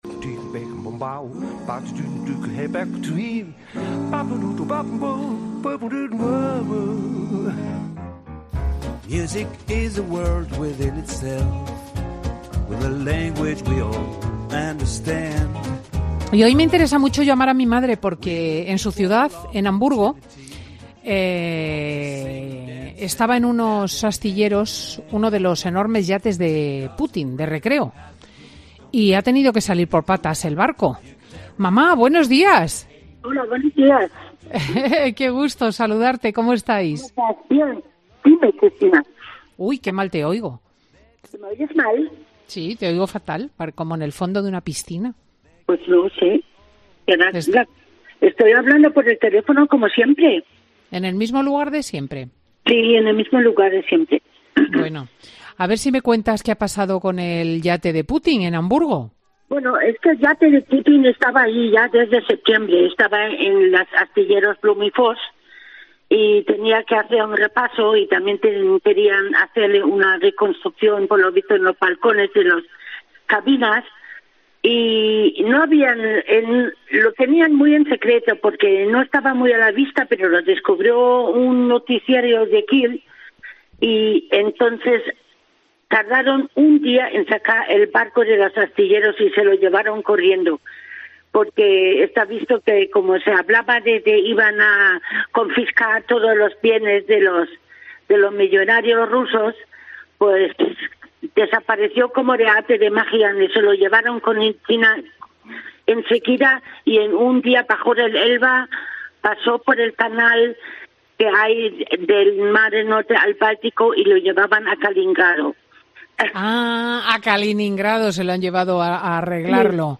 Conversaciones con mi madre